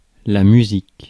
Ääntäminen
France: IPA: [la my.zik]